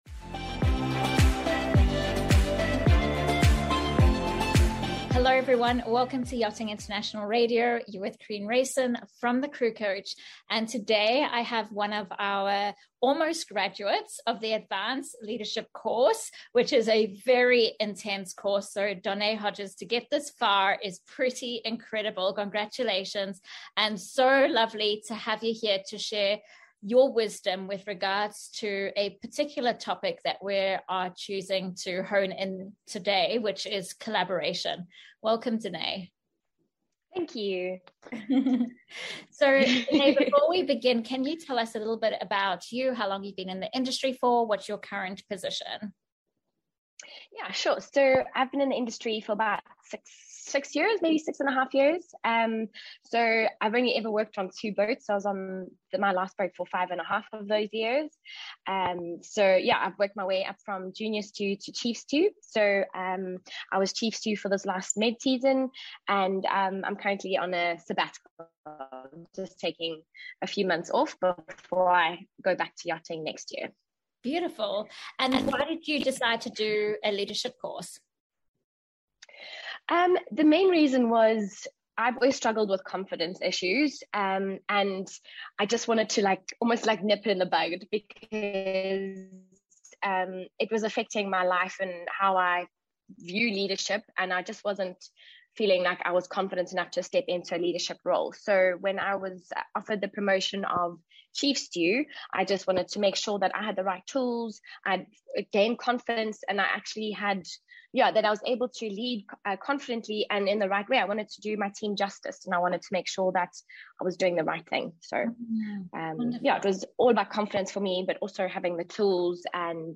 We focus on one particular module being how to get teams to work collaboratively together. We highlight characteristics of a fractured team and how to move them to a cohesive team. To access these great tips watch this interview or save it for later :)